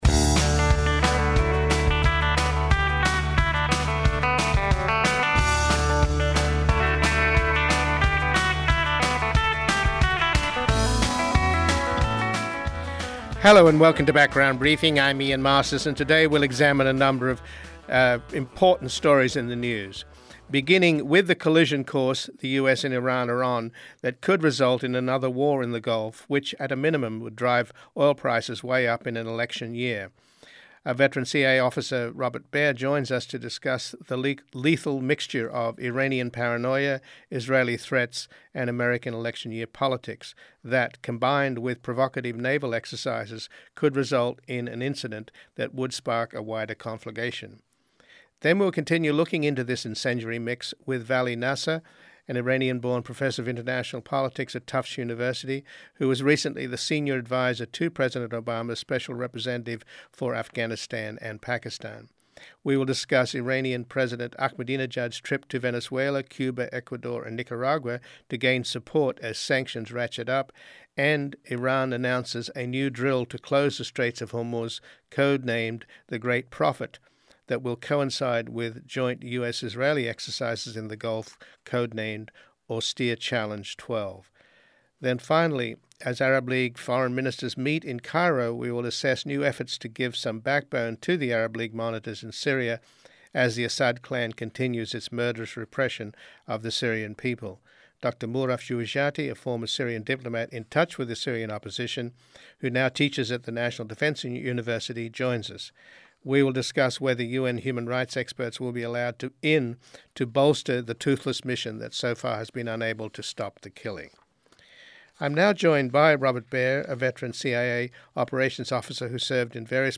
Full Program LISTEN TO FULL PROGRAM Part 1 We begin with the collision course the U.S. and Iran are on that could result in a another war in the Gulf which at a minimum would drive oil prices way up in an election year. A veteran CIA officer Robert Baer joins us to discuss the lethal mixture of Iranian paranoia, Israeli threats and American election-year politics that, combined with provocative naval exercises, could result in an incident that would spark a wider conflagration.